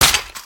46265b6fcc Divergent / mods / Soundscape Overhaul / gamedata / sounds / material / human / step / wpn_large2.ogg 16 KiB (Stored with Git LFS) Raw History
wpn_large2.ogg